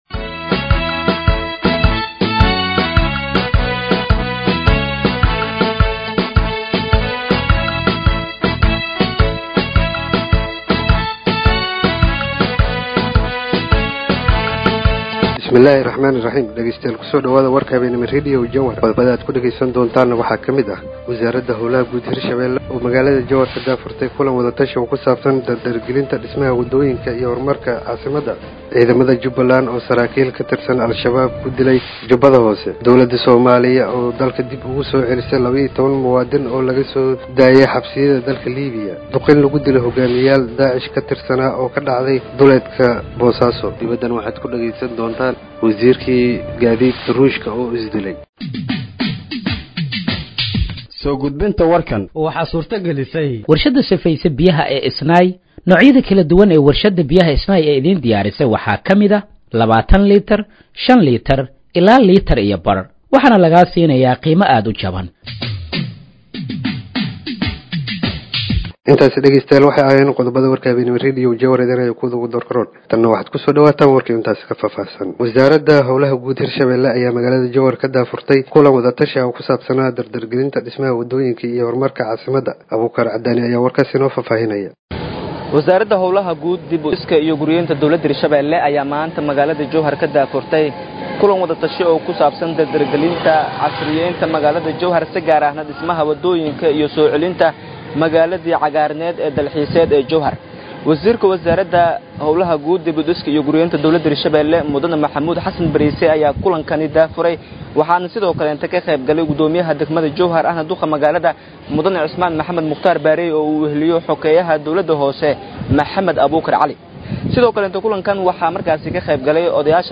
Dhageeyso Warka Habeenimo ee Radiojowhar 08/07/2025